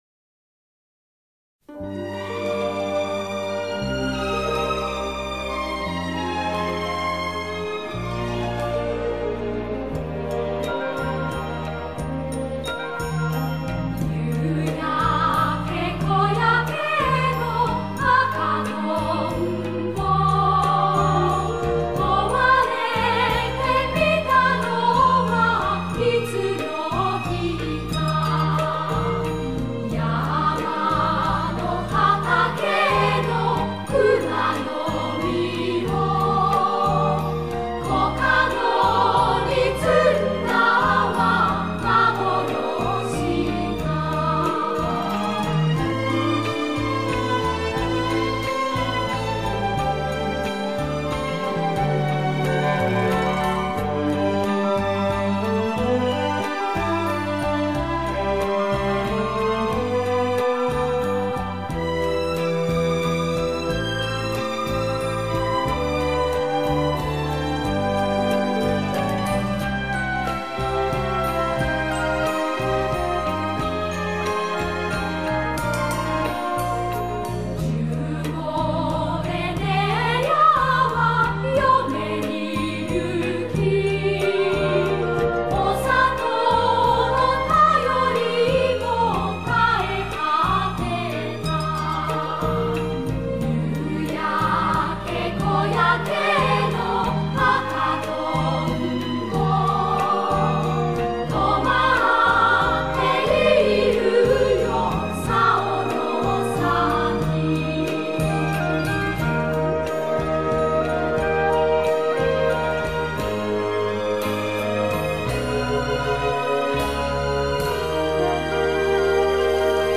回复: 求日语童声合唱《红蜻蜓》
～赤とんぼ 红蜻蜓～ うた：东京荒川少年少女合唱团 作词：三木露风 作曲：山田耕笮 .